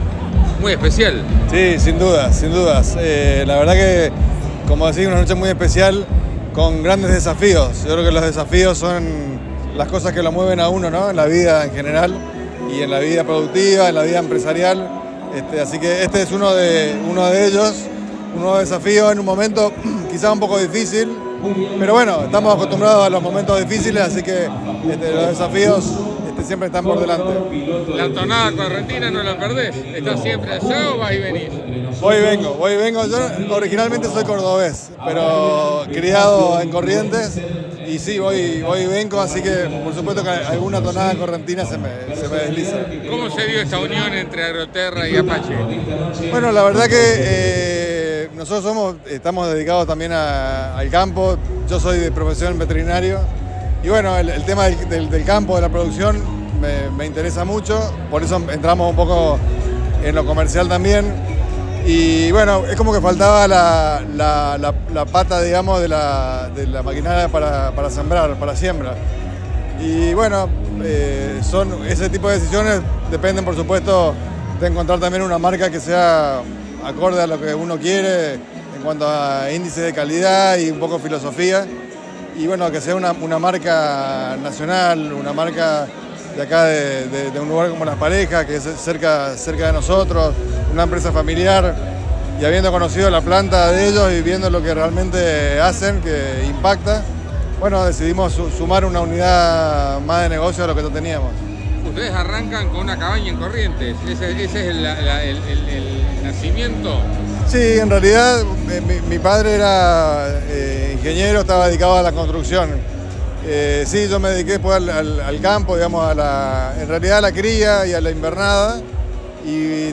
Cordobés de origen, pero con tonada correntina por llevar varios años en la provincia mesopotámica, es de profesión veterinario.